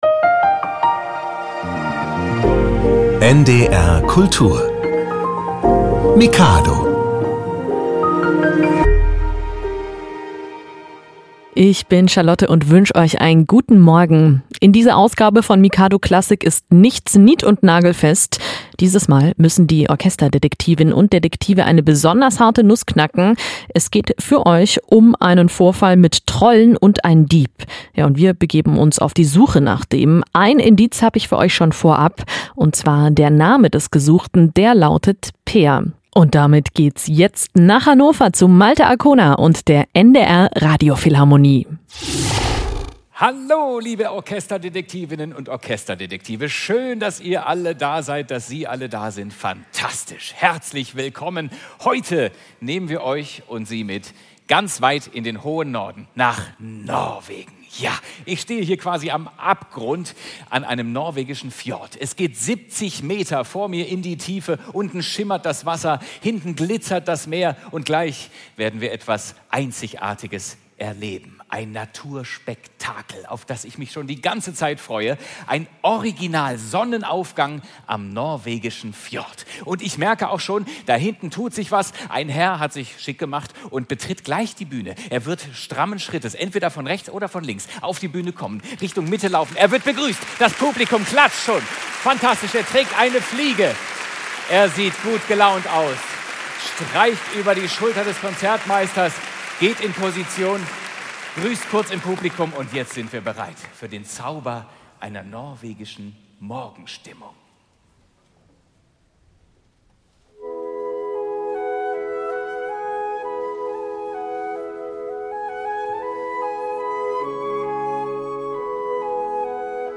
Wo ist Peer Gynt? Die Orchester Detektive suchen einen Dieb! ~ Hörspiele, Geschichten und Märchen für Kinder | Mikado Podcast